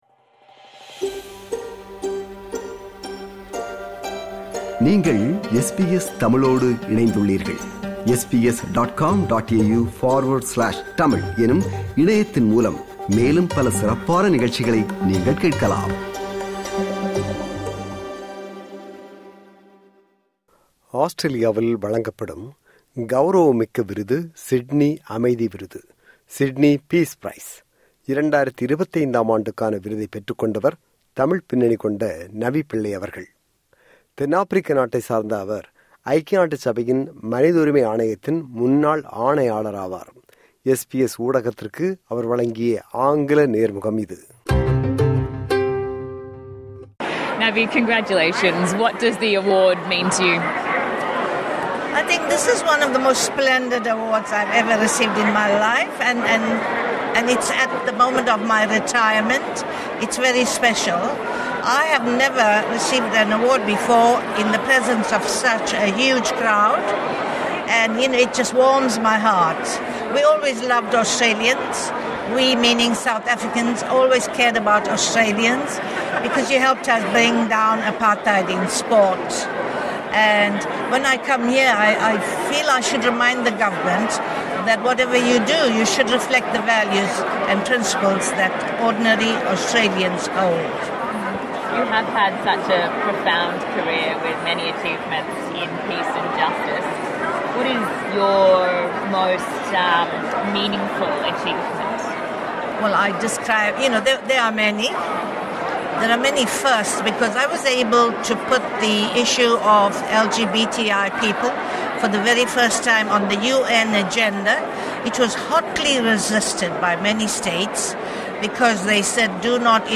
SBS ஊடகத்திற்கு அவர் வழங்கிய நேர்முகம் இது.